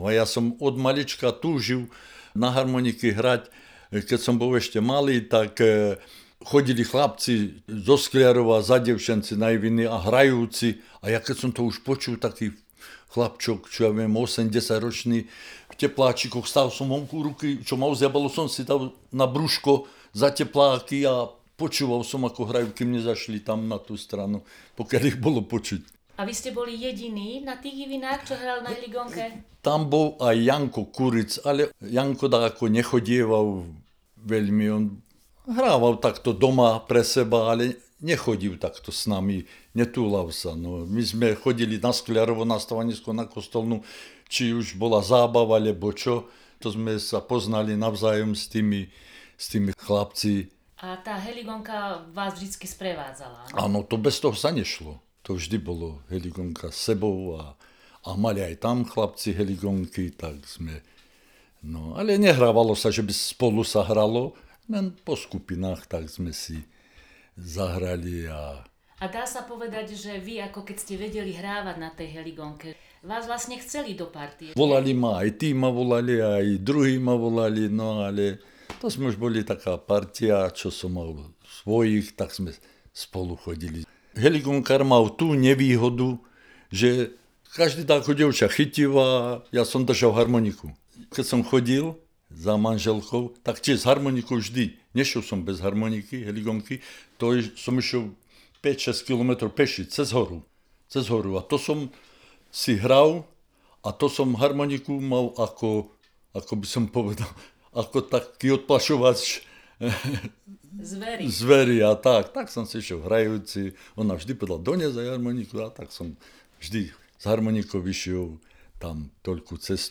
Hra na heligónke z Dúbrav 001-02